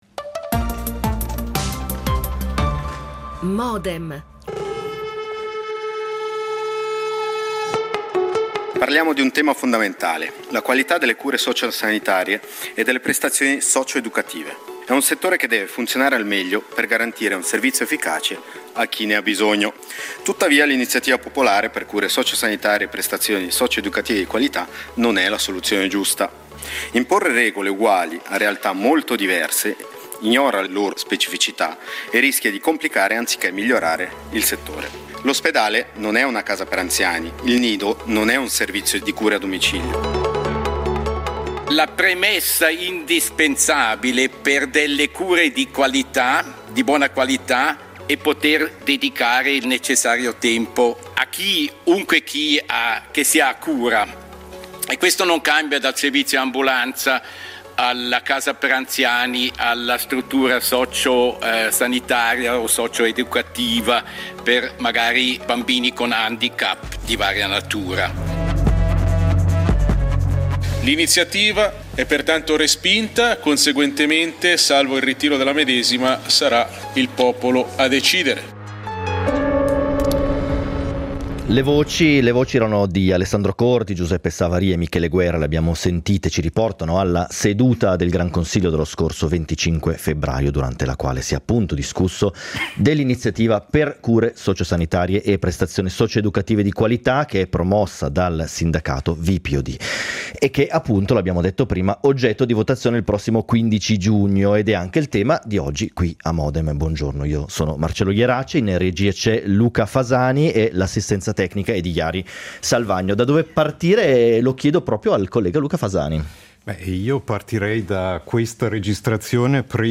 Sul tema in votazione il 15 giugno dibattono due Granconsiglieri: · Giulia Petralli , Verdi e sindacalista VPOD · Matteo Quadranti , PLR